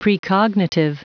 Prononciation du mot precognitive en anglais (fichier audio)
Prononciation du mot : precognitive